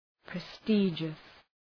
{pre’stıdʒəs}